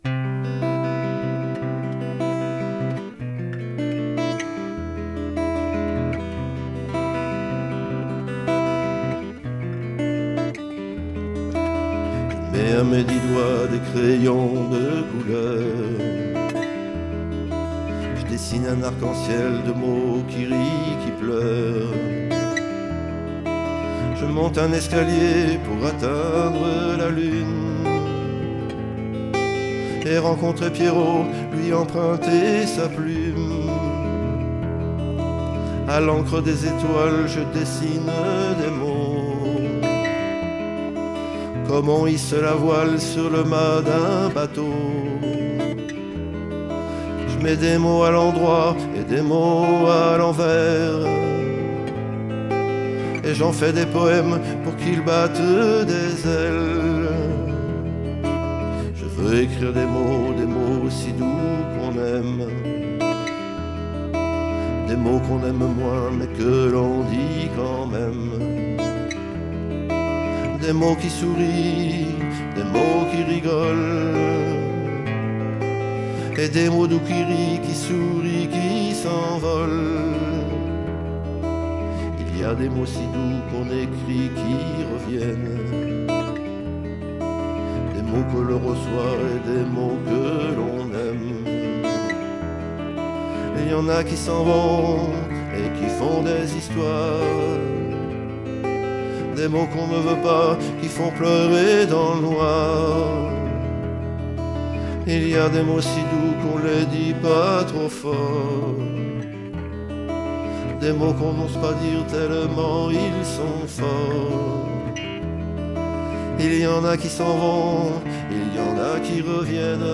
Chant, guitare